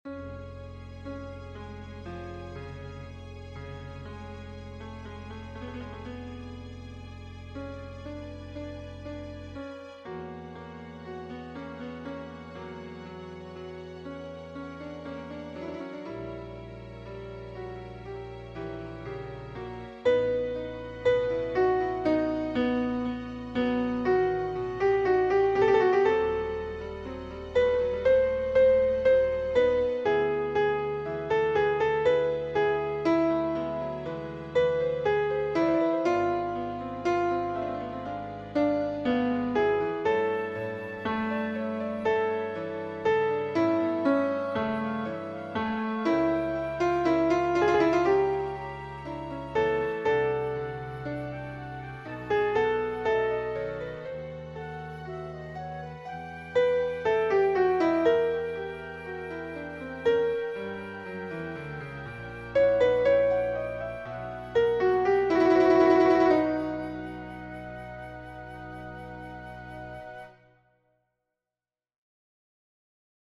MP3 Dateien von allen Chorstücken nach Register
BWV234-1b Christe_Alt.mp3